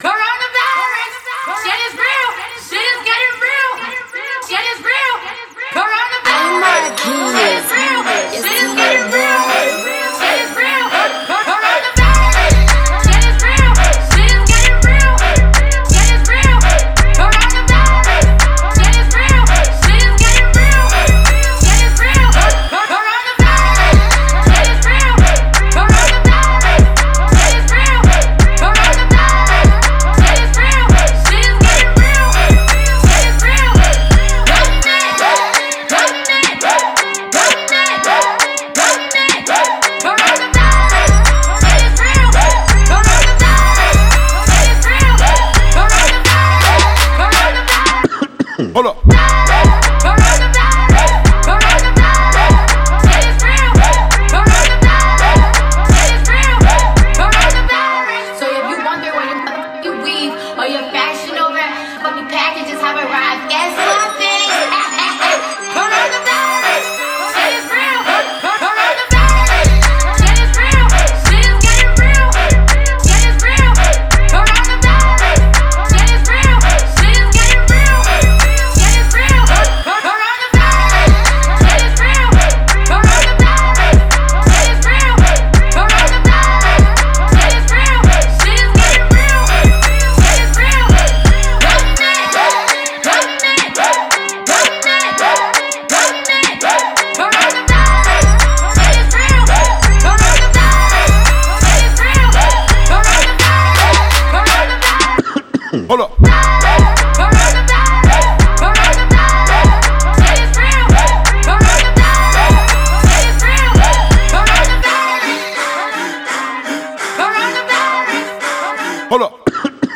это энергичный трек в жанре хип-хоп и EDM